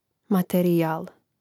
U izgovoru riječi materijal katkad se u brzom govoru gubi e, pa se ta riječ izgovara matrijal, ali to je pogrešno.